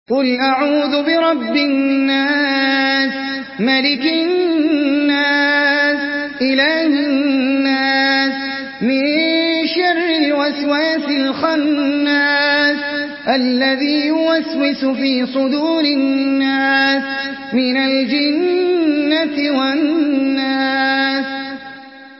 Surah আন-নাস MP3 by Ahmed Al Ajmi in Hafs An Asim narration.
Murattal Hafs An Asim